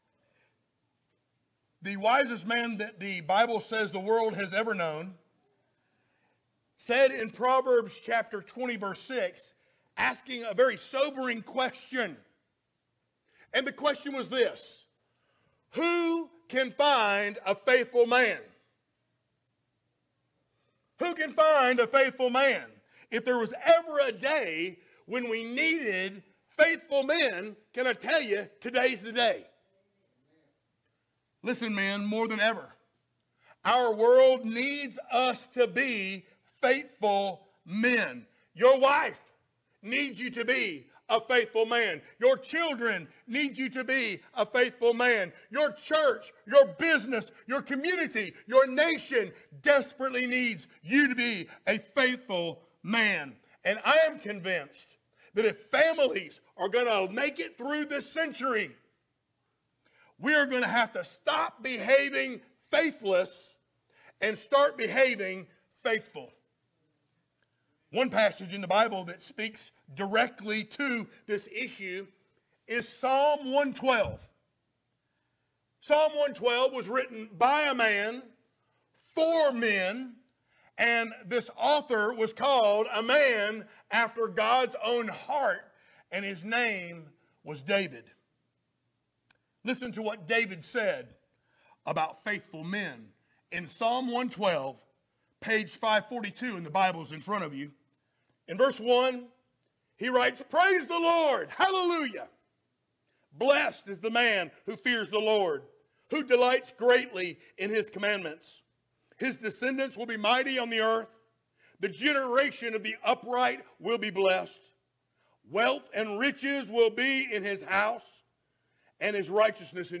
Series: sermons
Psalm 112:1-10 Service Type: Sunday Morning Download Files Notes Topics